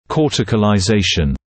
[ˌkɔːtɪkəlaɪ’zeɪʃn][ˌкоːтикэлай’зэйшн]кортикализация, формирование кортикального слоя